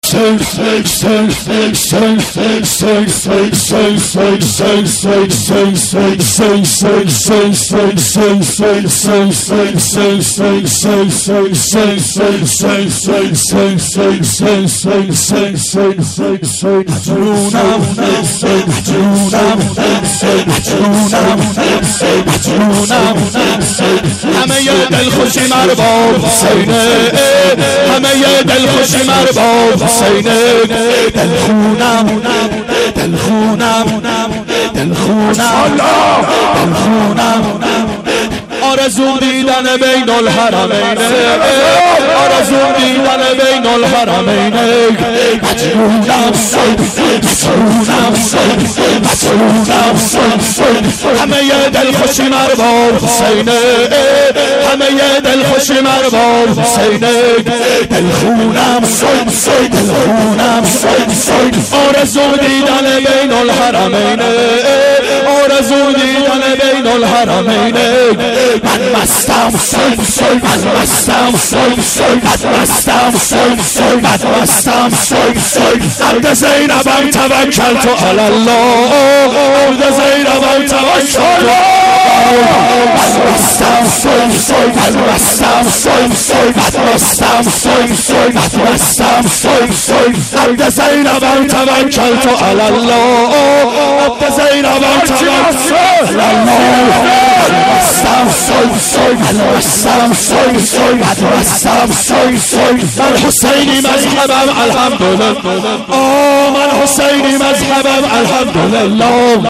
ظهر شهادت حضرت زهرا سلام الله علیها1392 هیئت شیفتگان حضرت رقیه س